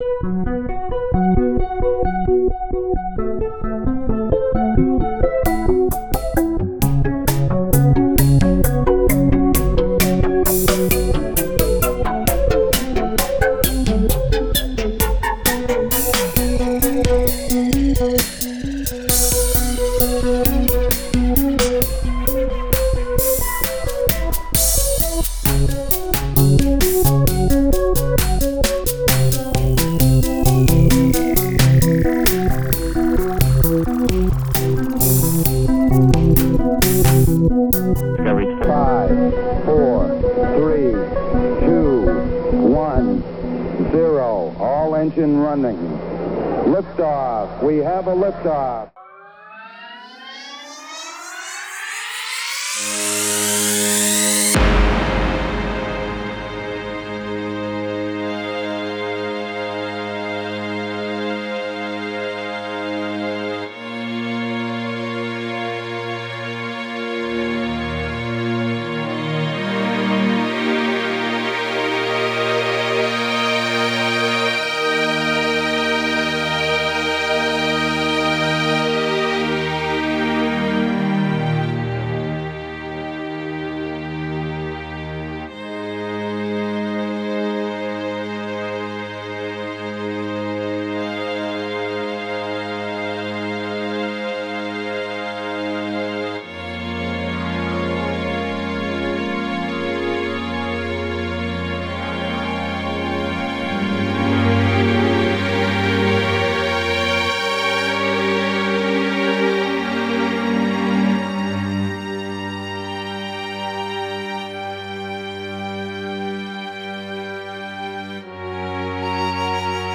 Sans compter quelques apparitions chantées puisque j’ai également écrit la musique…
Dame Géronimo Extrait musical du voyage temporel http